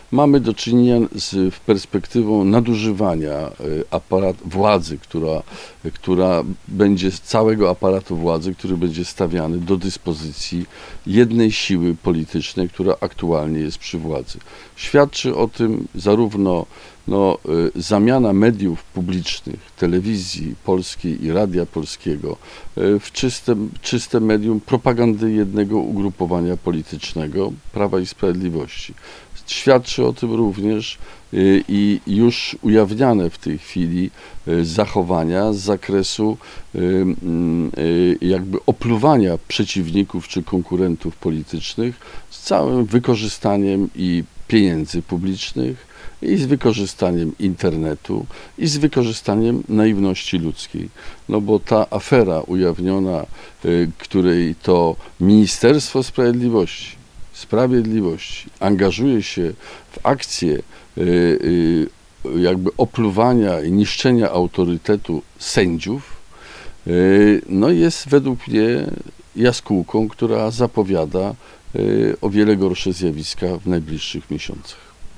Były prezydent w wywiadzie, jakiego udzielił Radiu 5, mówił o swoich przemyśleniach na temat aktualnej, przedwyborczej sytuacji politycznej w kraju.